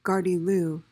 PRONUNCIATION:
(gar-dee-LOO)